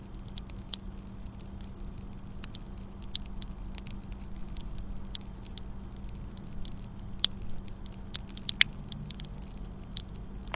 描述：烤栗子在街道上。
从Escola Basica Gualtar（葡萄牙）和它的周围的领域录音，由8岁的学生做。